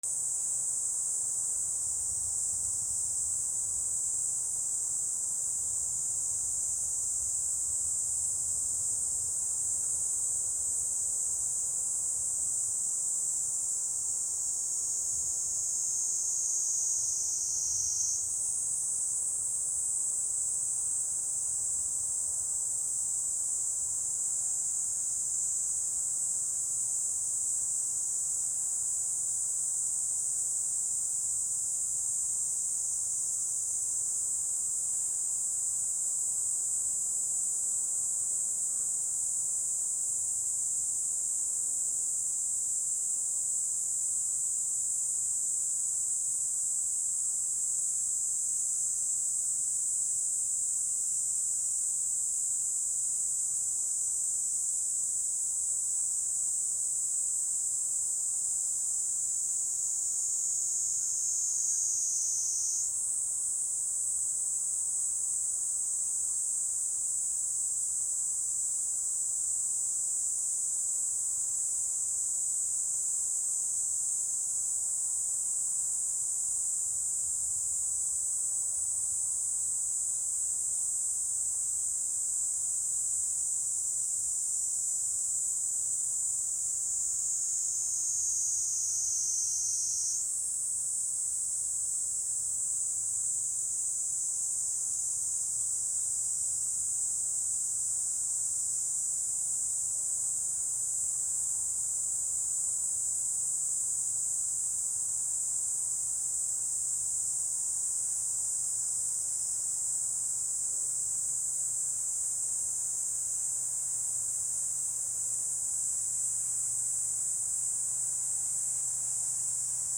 / B｜環境音(自然) / B-25 ｜セミの鳴き声 / セミの鳴き声_70_初夏
セミの鳴き声 大阪にいなそう
盛岡 D50